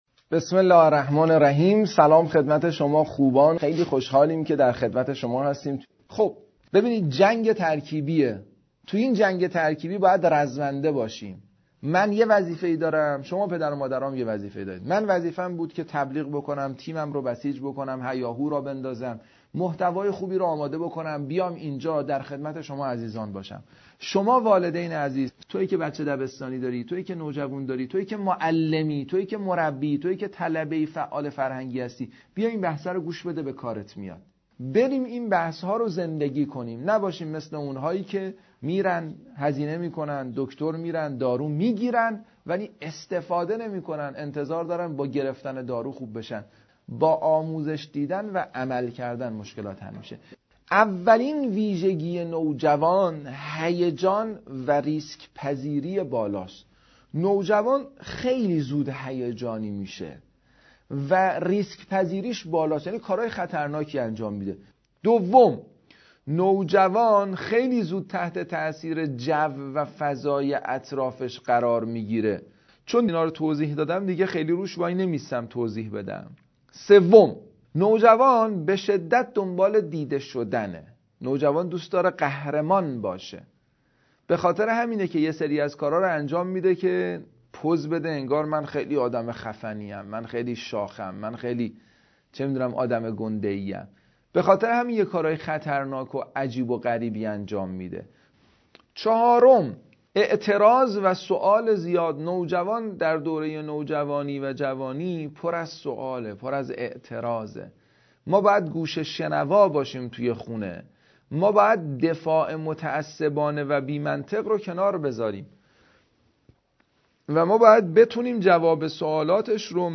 در این مجموعه وبینار، به پرسش‌های محوری و اساسی زیر پاسخ داده می‌شود: